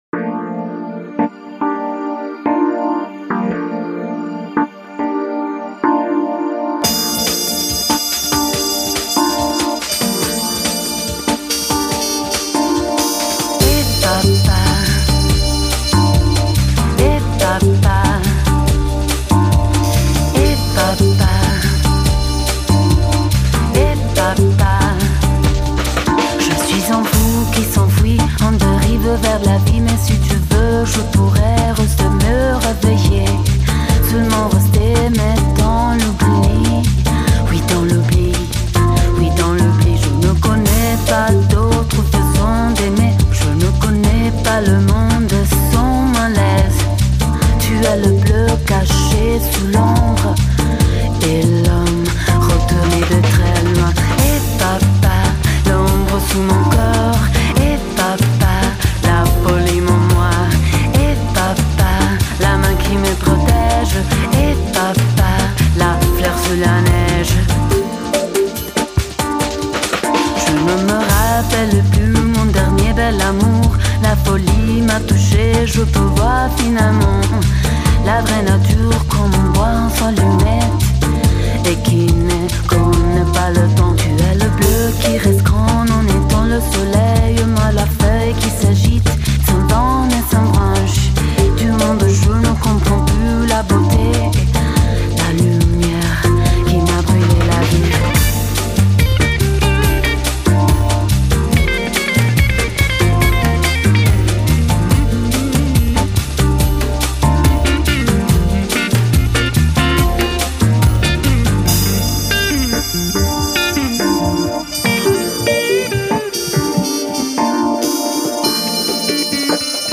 Electronic, Lounge, Downtempo, Listening | 2008 | 164Mb
整张专辑收录了大师的精品之作 旋律优美 节拍舒缓 优雅浪漫